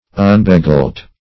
Unbegilt \Un`be*gilt"\, a. Not gilded; hence, not rewarded with gold.